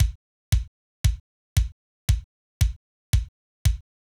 MOO Beat - Mix 11.wav